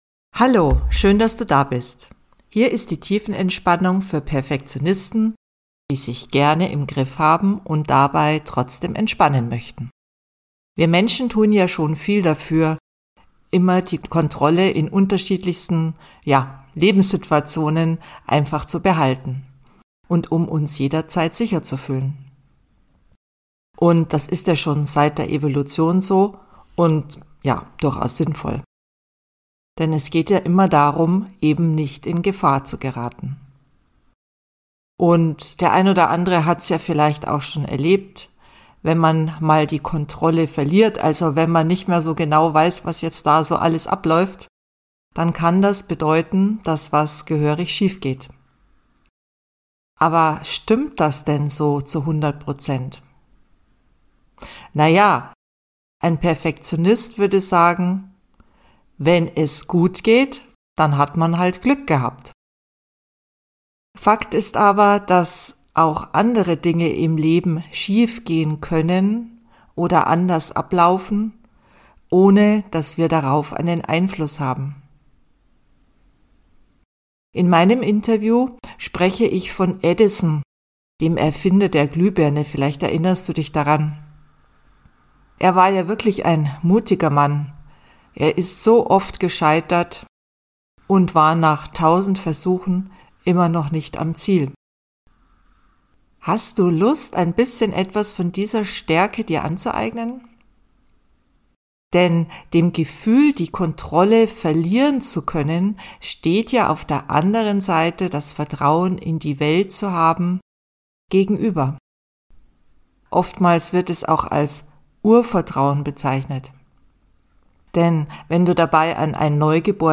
Probiere es mit dieser Entspannungsreise und gebe dir die Chance runterzukommen und nicht gleich aufzugeben.